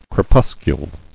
[cre·pus·cule]